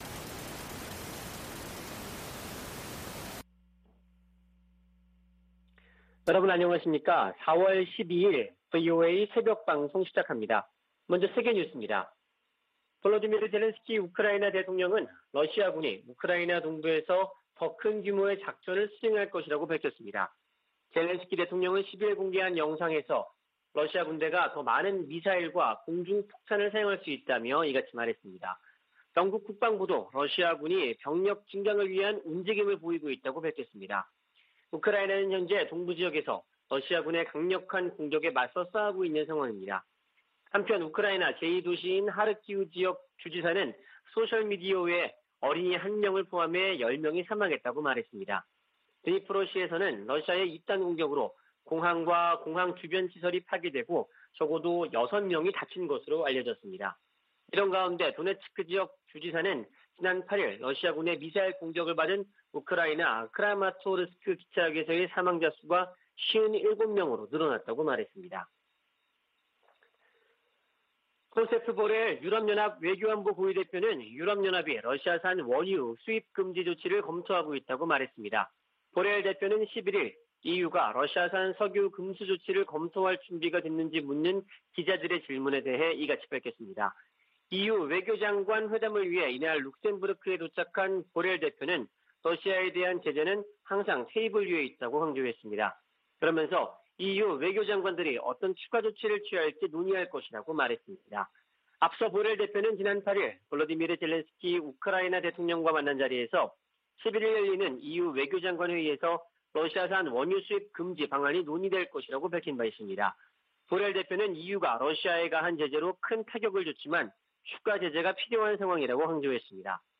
VOA 한국어 '출발 뉴스 쇼', 2022년 4월 12일 방송입니다. 미 국무부는 북한 비핵화 목표에 정책 변화가 없다고 밝히고, 대화에 나오라고 북한에 촉구했습니다. 미 국방부는 북한의 위협을 잘 알고 있다며 동맹인 한국과 훈련과 준비태세를 조정하고 있다고 밝혔습니다. 북한이 추가 핵실험을 감행할 경우 핵탄두 소형화를 위한 실험일 수 있다는 전문가들의 지적이 이어지고 있습니다.